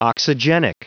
Prononciation du mot oxygenic en anglais (fichier audio)
Prononciation du mot : oxygenic